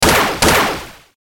mech_fire.ogg